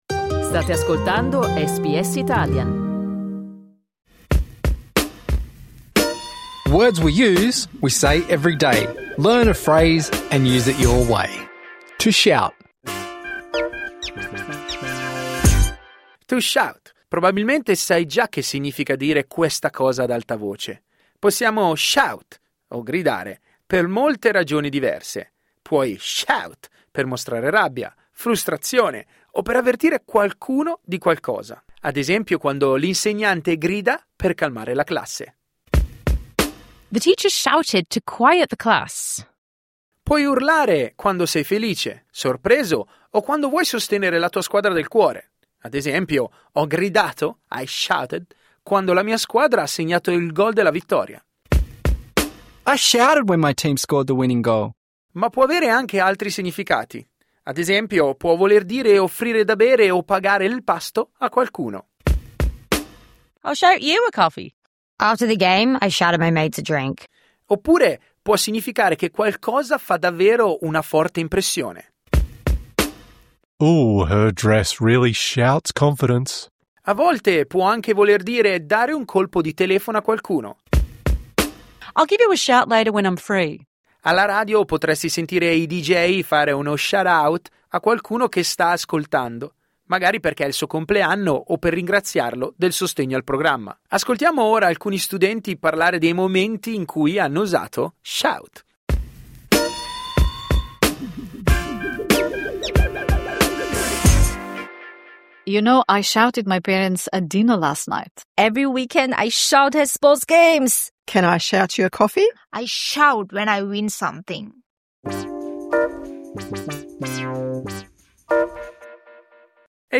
Impara una nuova espressione per rendere il modo in cui parli inglese più sciolto e variegato. Words We Use è una serie bilingue che ti aiuta a comprendere espressioni idiomatiche come "to shout".